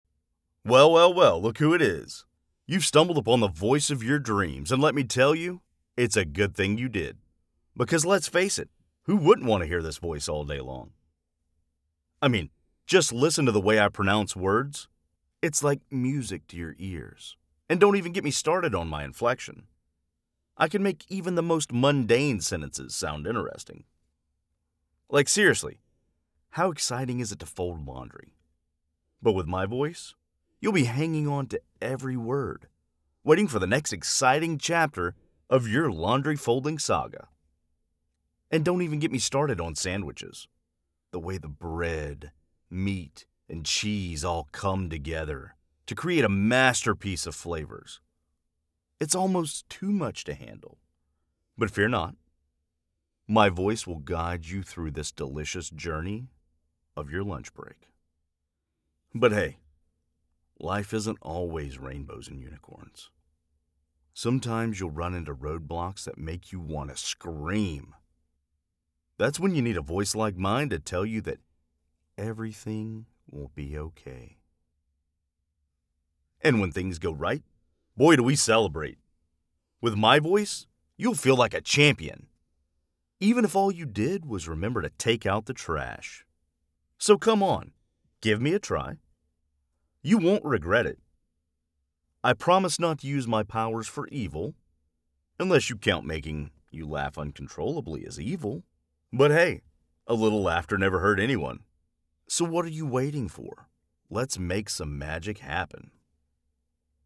Accents and Dialects
Southern; Neutral; Midwestern